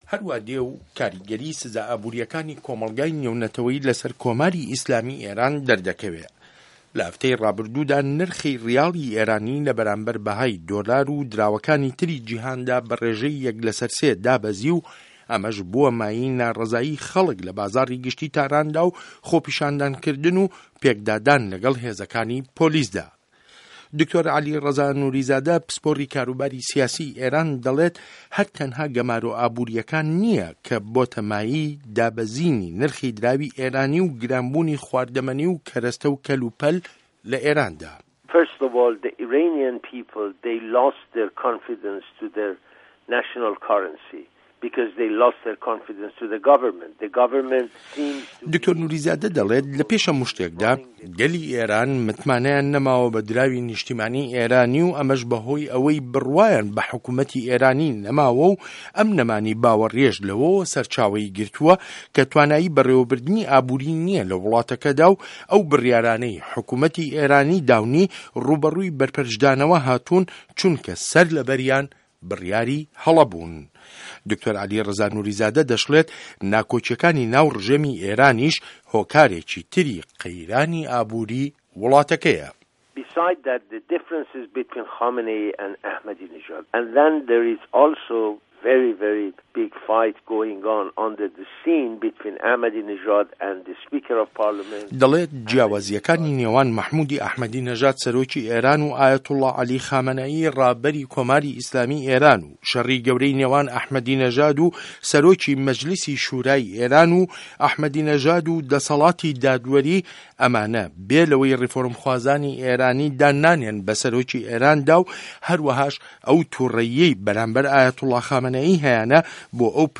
ڕاپۆرت له‌سه‌ر لێدوانه‌کانی دکتۆر عه‌لی ڕیزا نوریزاده‌